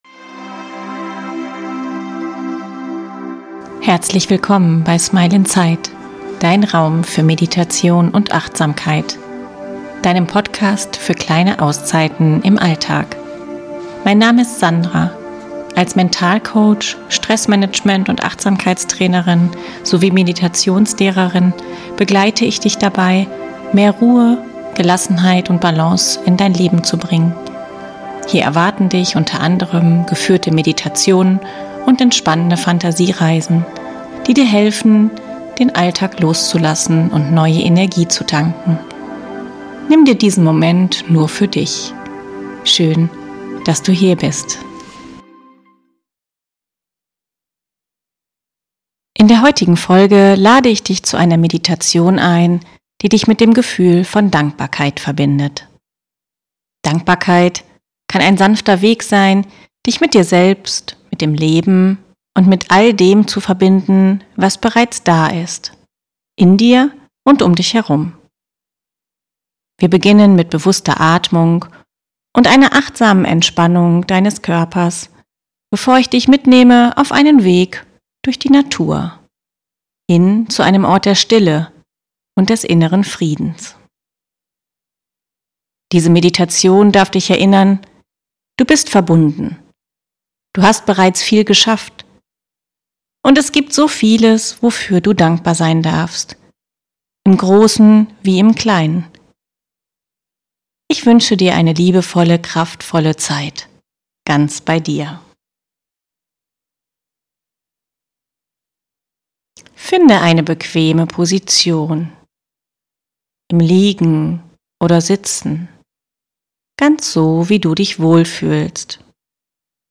In dieser sanften, geführten Meditation nimmst du dir bewusst Zeit für das, was im Alltag oft übersehen wird: Dankbarkeit.